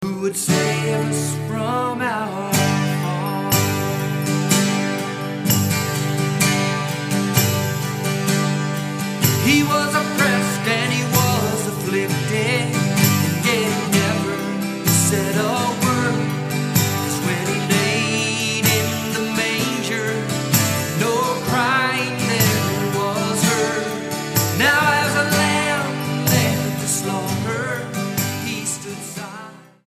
STYLE: Pop
performing nicely arranged, anthemic rock-gospel.